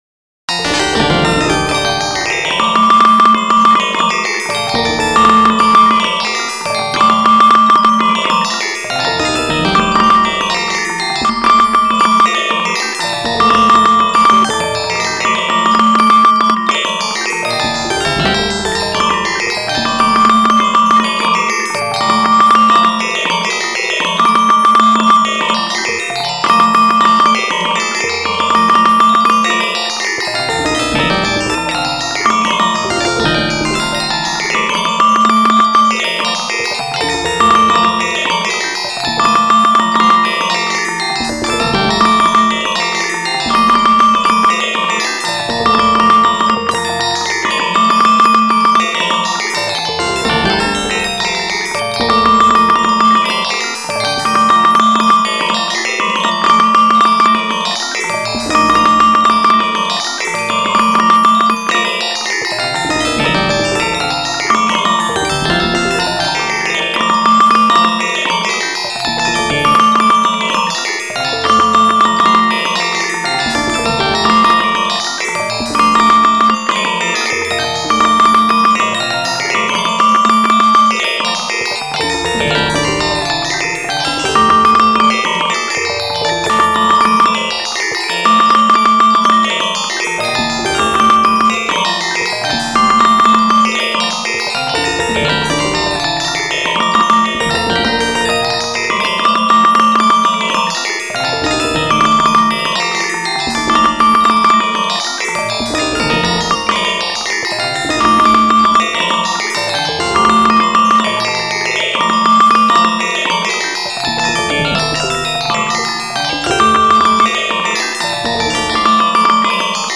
These mp3s were rendered to audio with the Roland Sound Canvas.
syncopated [4 mins] time for bed [4 mins] vibraphone cv texture
So, in these recordings, they fade out at the end of the clip.
vibraphone_cv_texture_4_mins.mp3